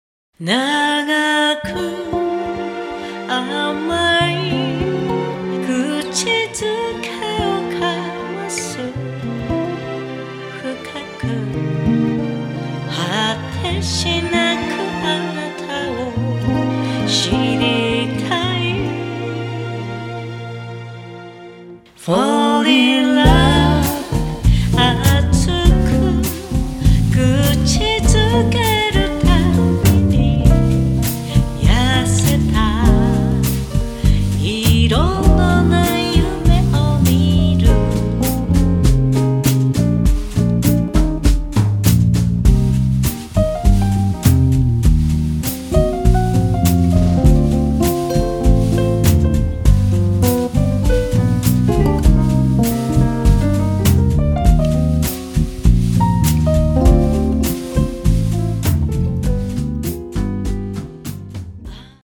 ジャズの枠を超え、ラテン、ファンク、ロック、レゲエなど
あらゆるビートのエッセンスを取り込んだアンサンブル。
Vocal
Guitar
Bass
Piano